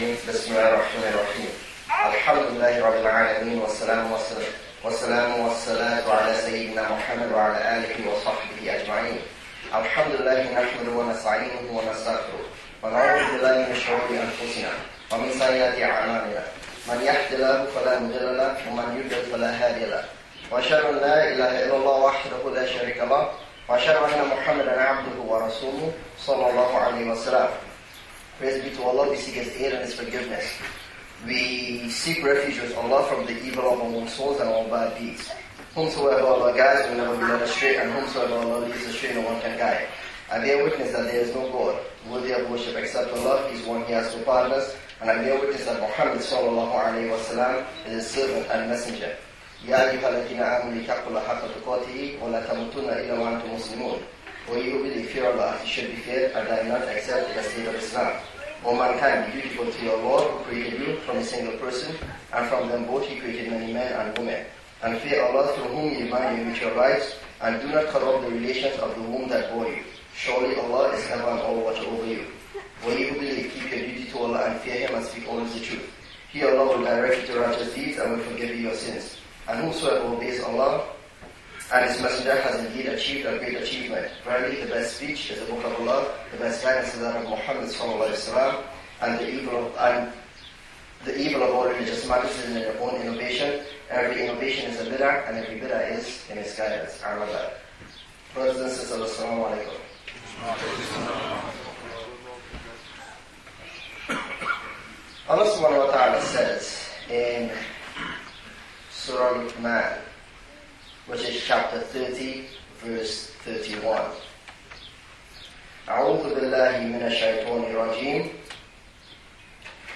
Jumu'ah Khutbah: Confused Realities: Transforming Chaos into Focus (5/18/12 | 26/6/1433 - Masjid Quba)